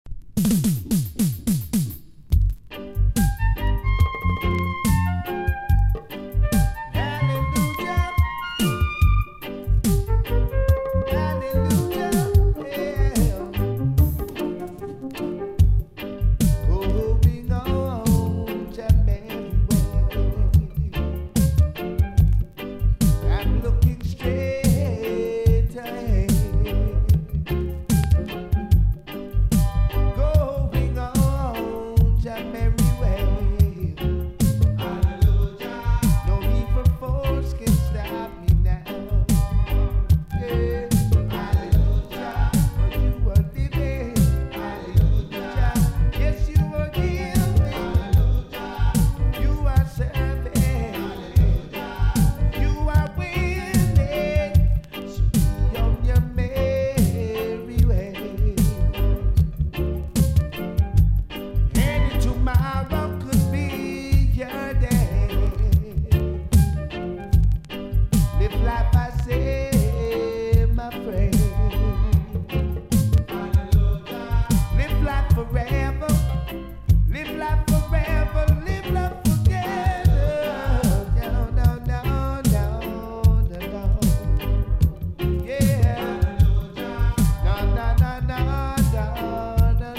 • REGGAE-SKA
デジタルなドラムに彼の緩いヴォーカルが冴え渡る好盤!!
# ROOTS